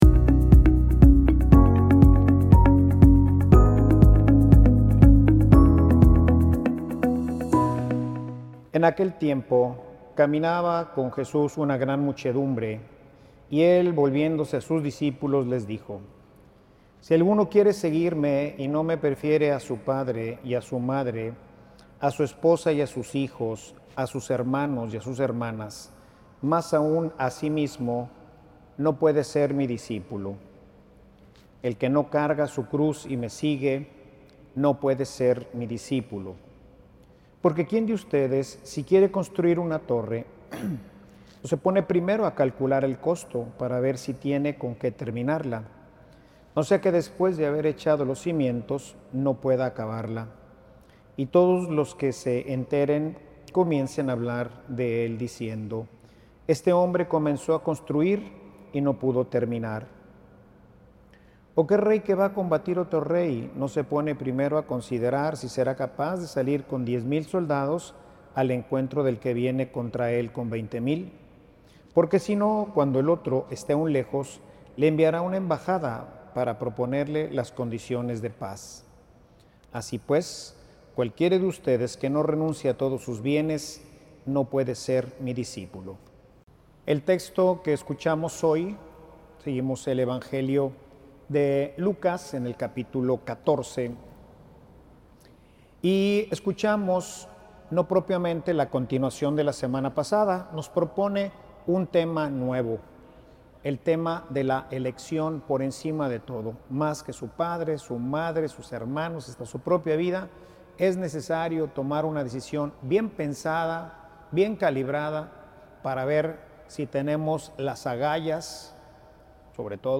Homilia_Quieres_ser_perfecto.mp3